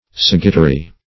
sagittary - definition of sagittary - synonyms, pronunciation, spelling from Free Dictionary
Sagittary \Sag"it*ta"ry\, n. [See Sagittarius.]